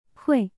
ui"wey"